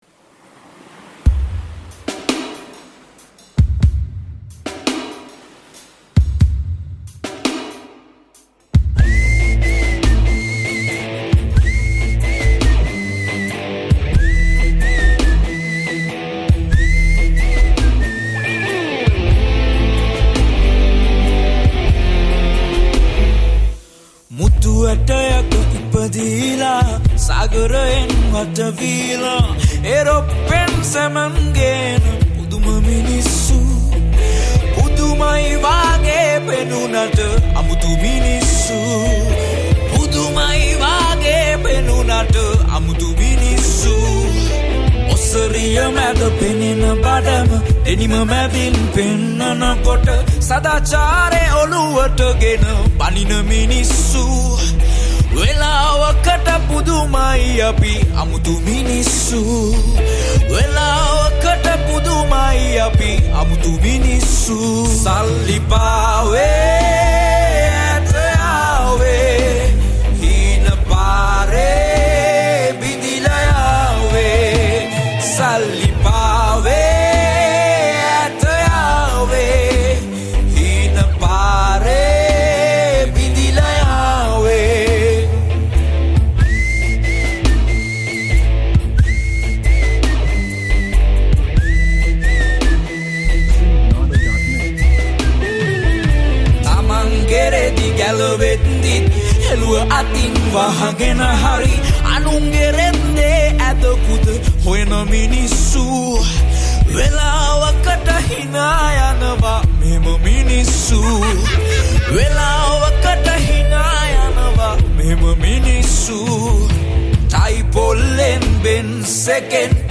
Vocals
Guitar